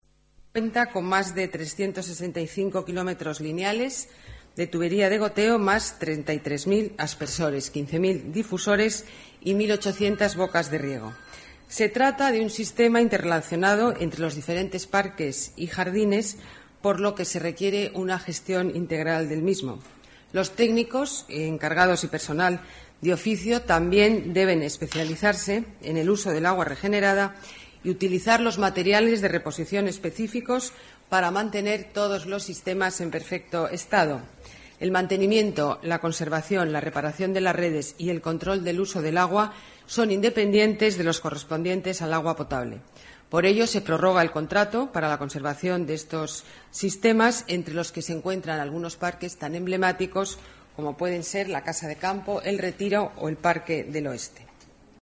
Nueva ventana:Declaraciones alcaldesa Ana Botella: Redes de riego con agua reciclada